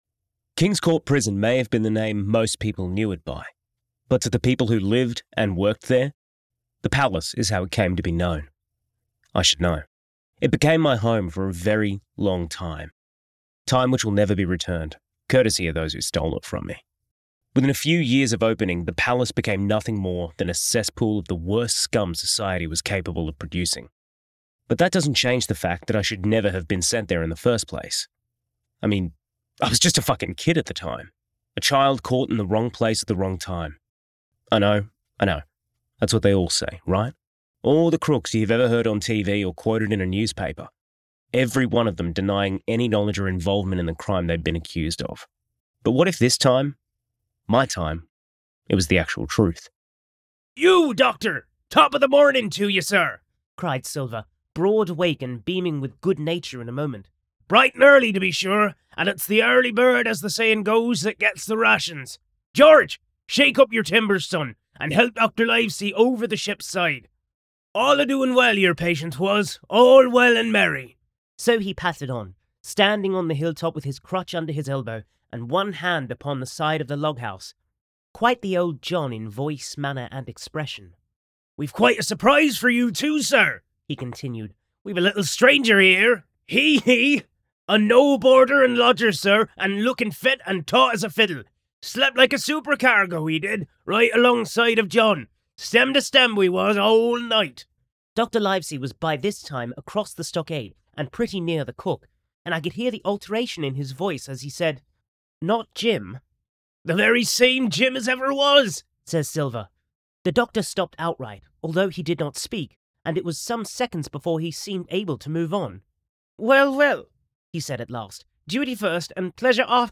Young Australian Voice Artist with a hint of chaos magic
Commercial Demo
English - Australian
Teen
Young Adult